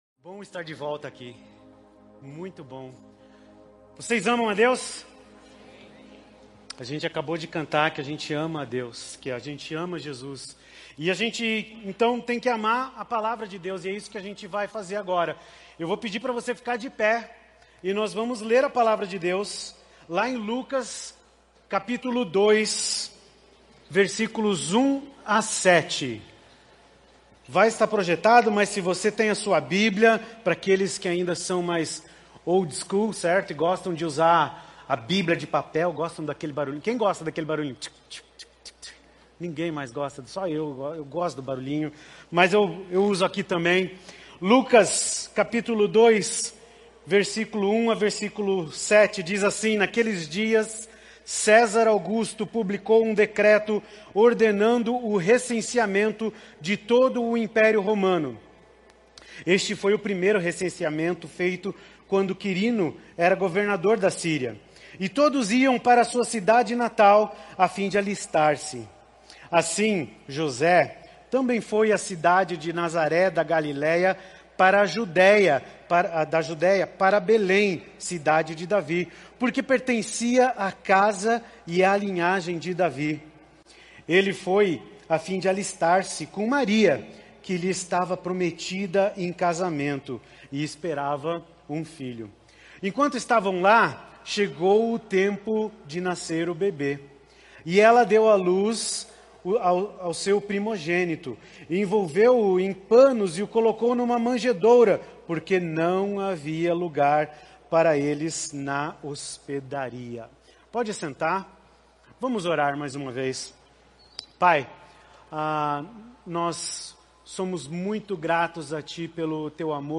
Igreja Batista do Bacacheri